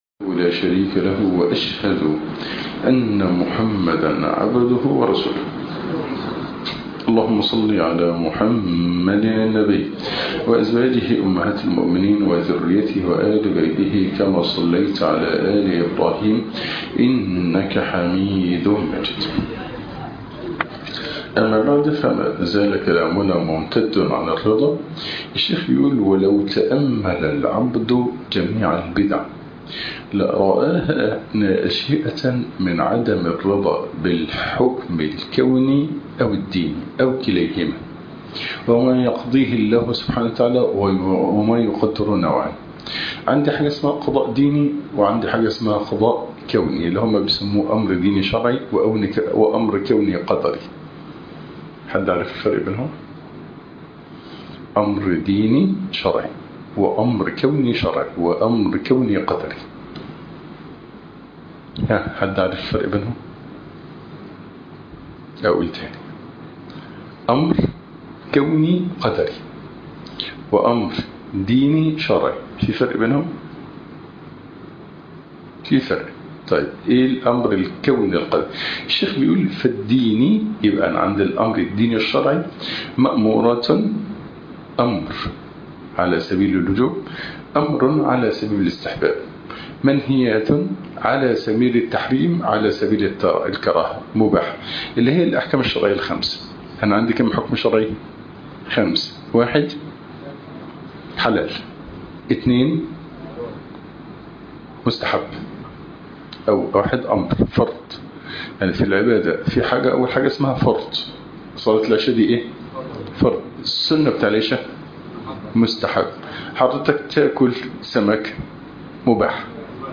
( فقه الرضا ) الدرس الثامن